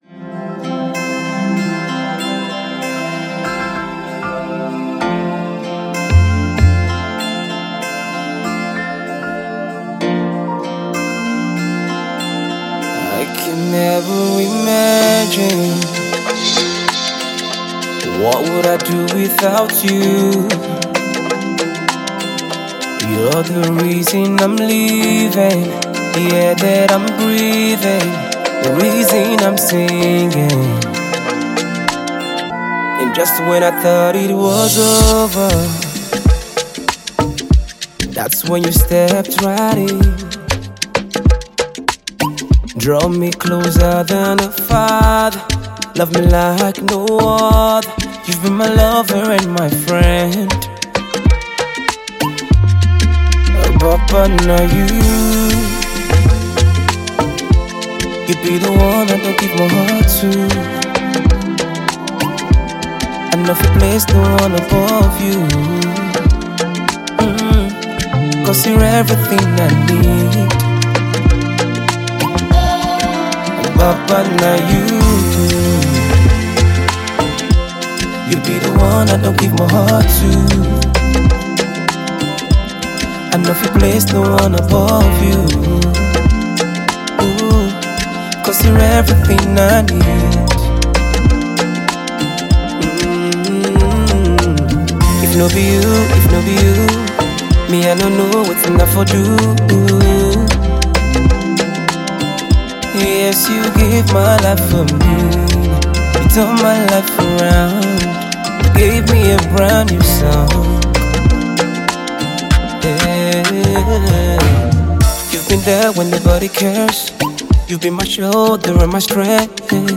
contemporary sounds with heartfelt lyrics